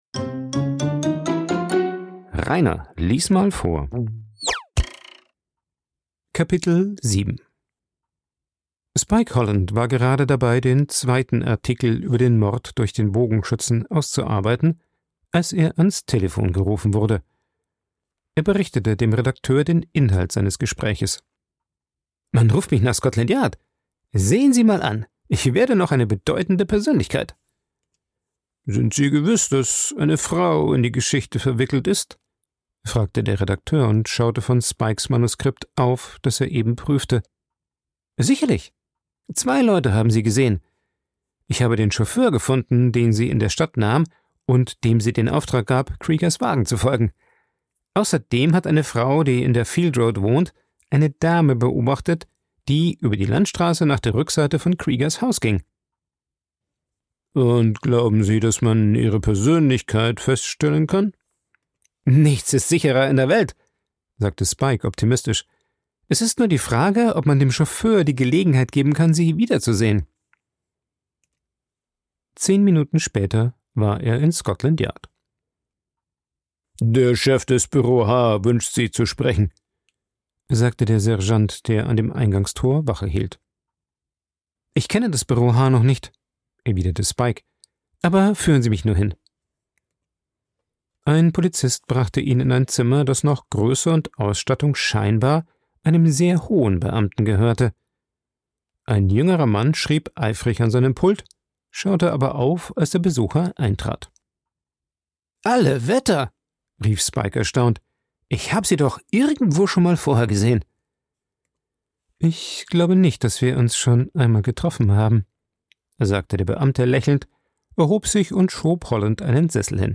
Ein Vorlese Podcast
Coworking Space Rayaworx, Santanyí, Mallorca.